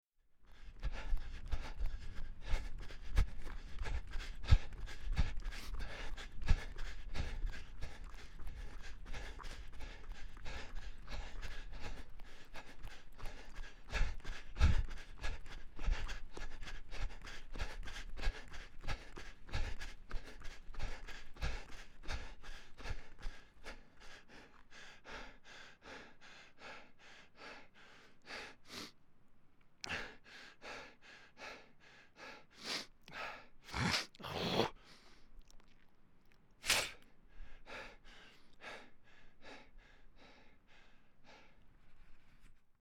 Звуки бегущего человека
Звук тяжелого дыхания при интенсивном беге с одышкой